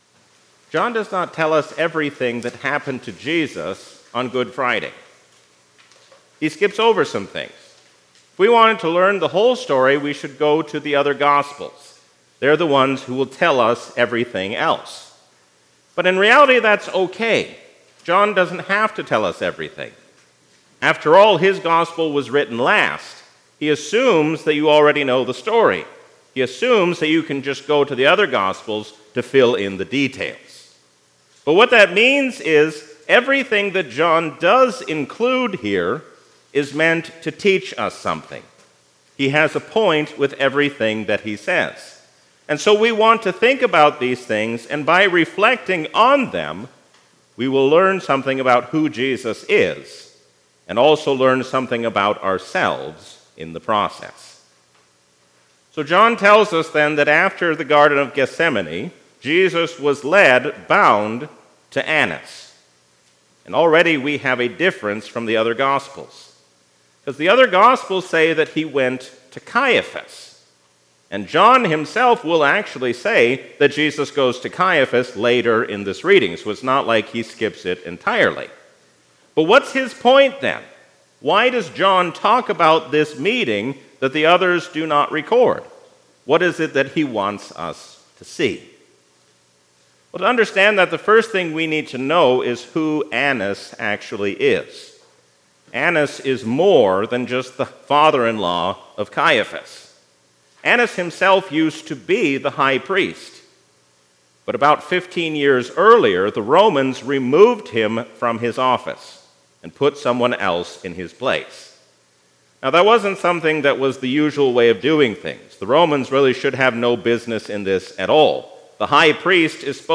A sermon from the season "Lent 2024." All those who know the truth listen to the voice of Jesus, because He is the Truth who has come to set us free.